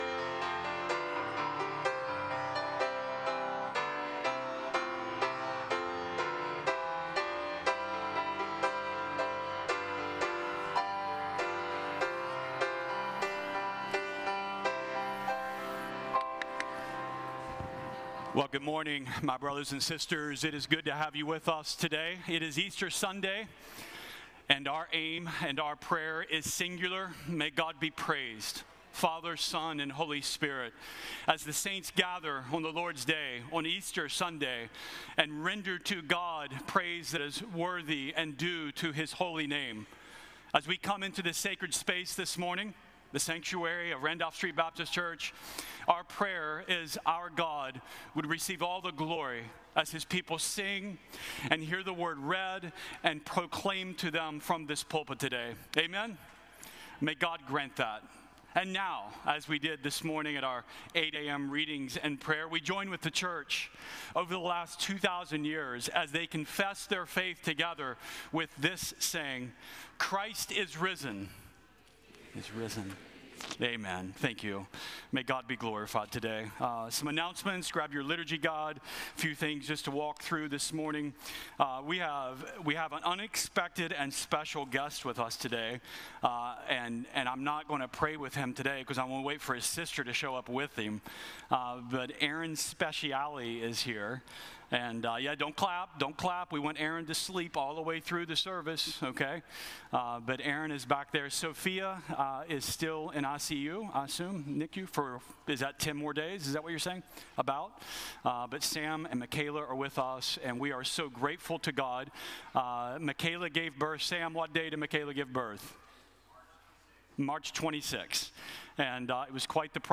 From Series: "Stand Alone Sermons"
Sermons preached outside of normal exegetical sermon series, many times by guest speakers.